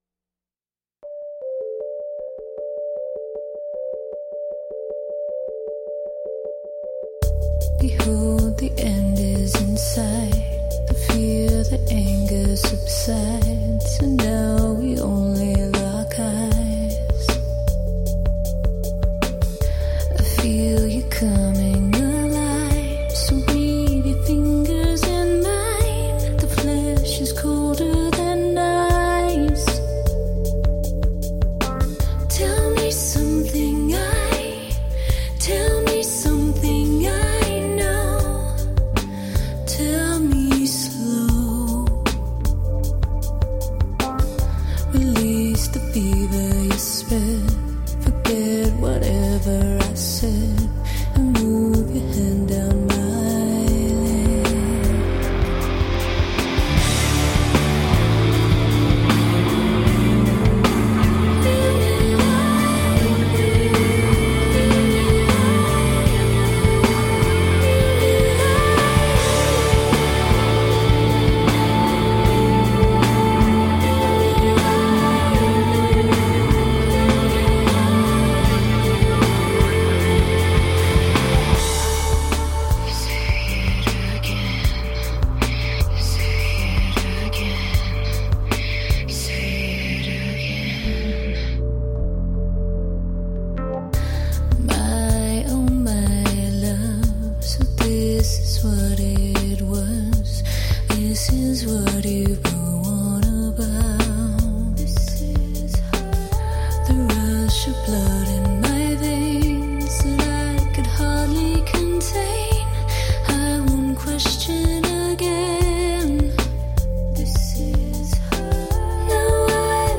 Moody blend of electronica and rock.
Tagged as: Electro Rock, Rock